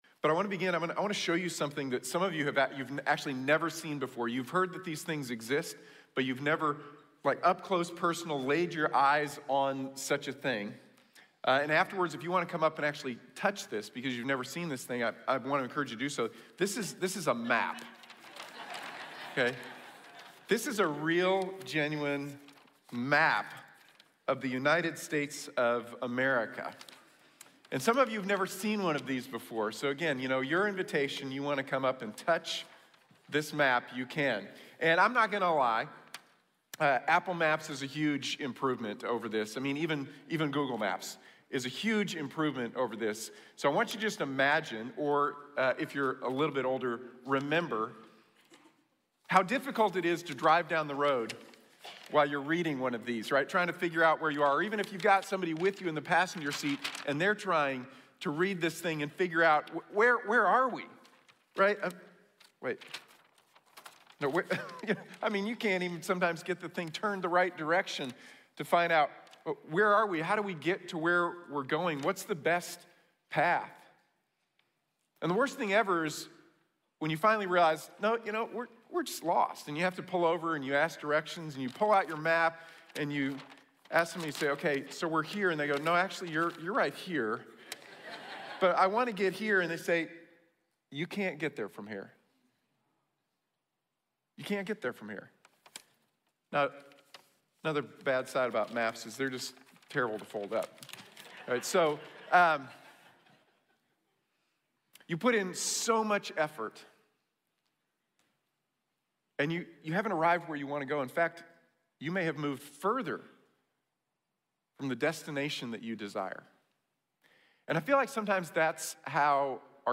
Find the Right Path | Sermon | Grace Bible Church